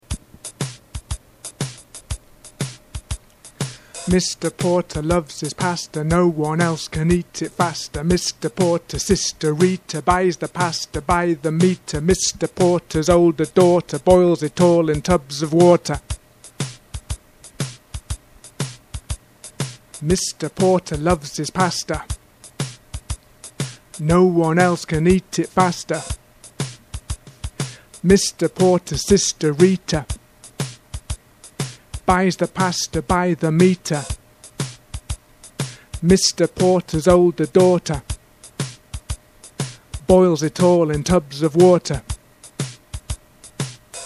Chanting
You hear the poem twice – once straight through with no pauses, and then with a pause after each line.
There is a drum beat keeping the rhythm.
3. Point out that the speaker reduces the vowel to schwa in all of the syllables shown in red.
Note that ‘his’ begins with a vowel sound in this instance because the speaker drops the ‘h’.